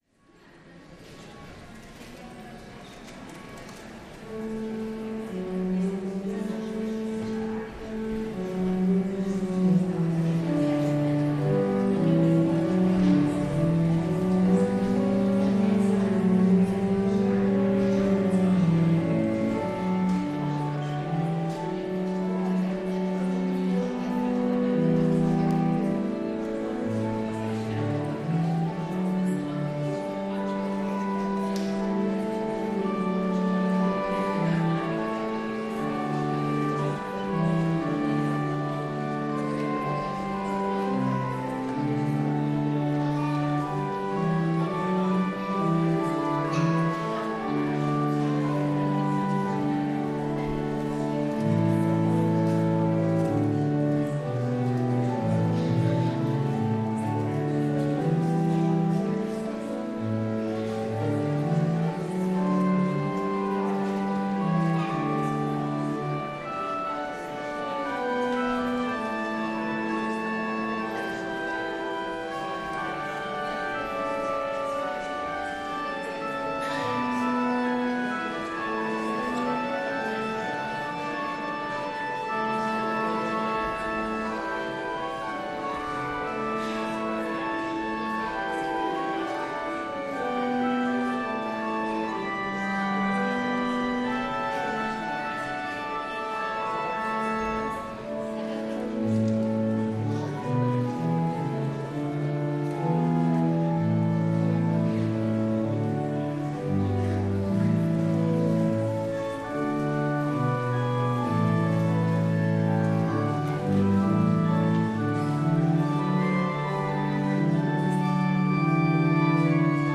(Példabeszédek könyve 25. fejezet 4-5. versei) Szeretett gyülekezet, kedves testvérek!